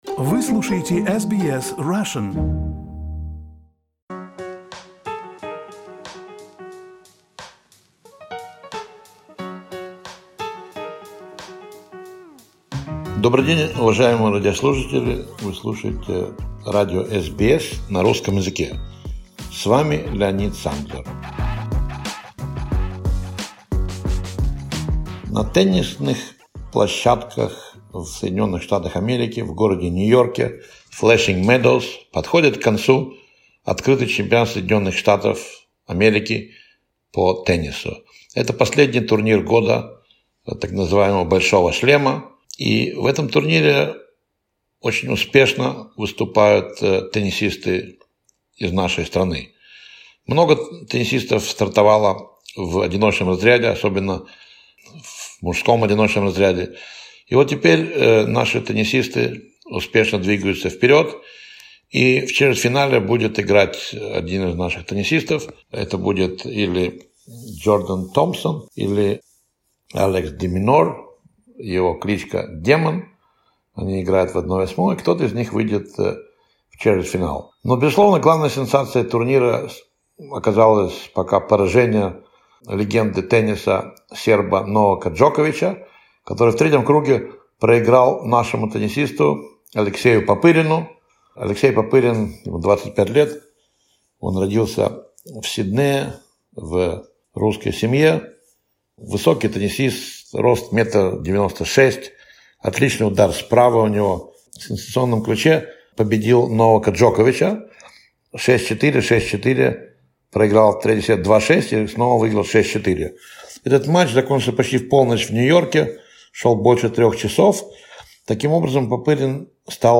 Спортивный обзор.